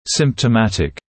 [ˌsɪmptə’mætɪk][ˌсимптэ’мэтик]симптоматичный, имеющий симптомы